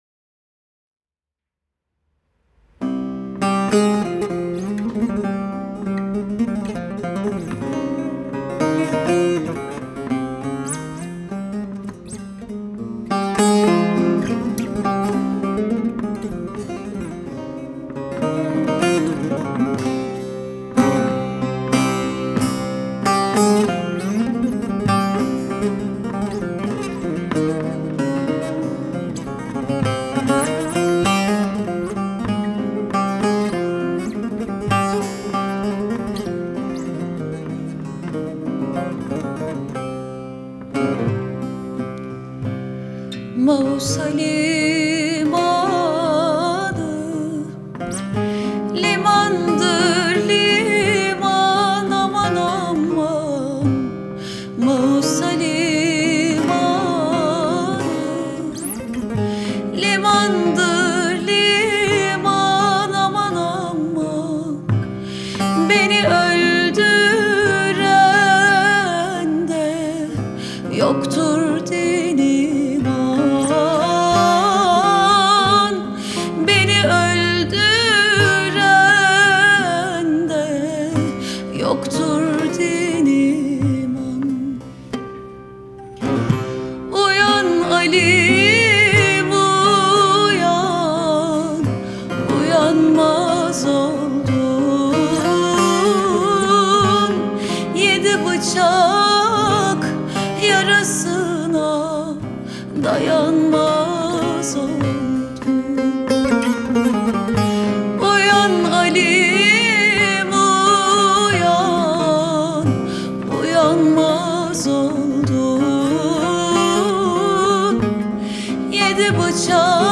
Трек размещён в разделе Турецкая музыка / Альтернатива.